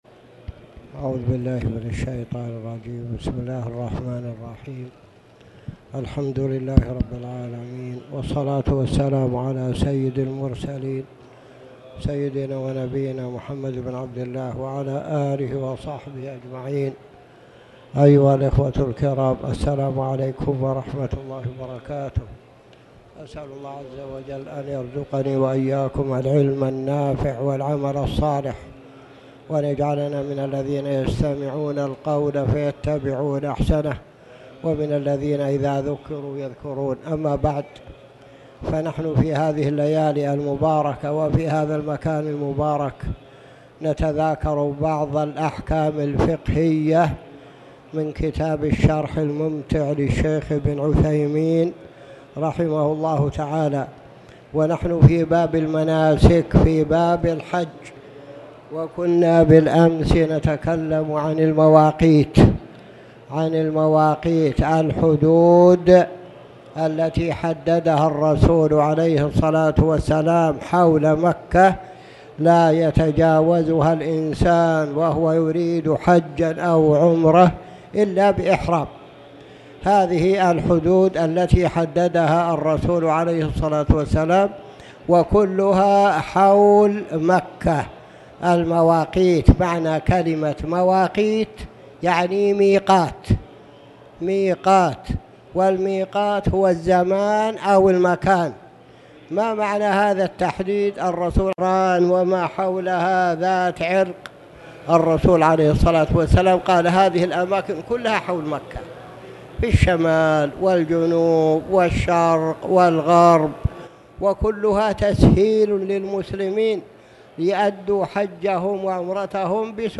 تاريخ النشر ٧ ذو القعدة ١٤٣٨ هـ المكان: المسجد الحرام الشيخ